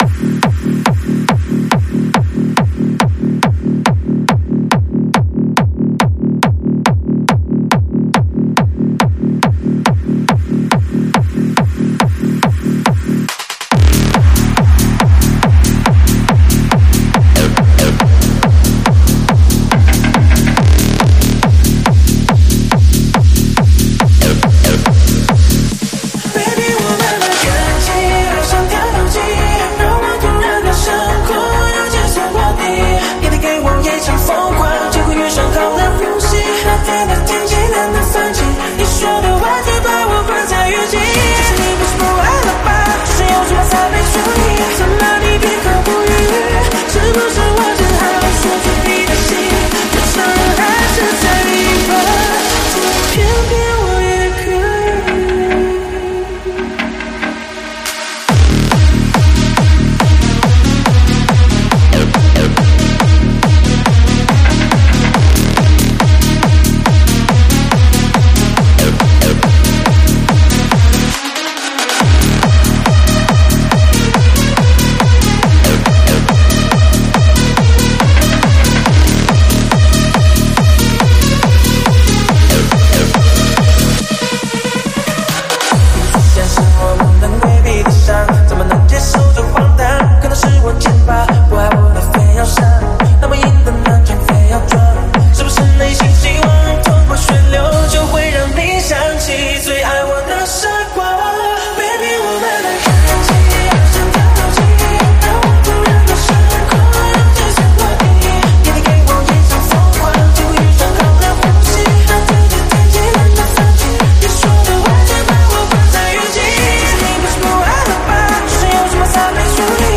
试听文件为低音质，下载后为无水印高音质文件 M币 15 超级会员 M币 7 购买下载 您当前未登录！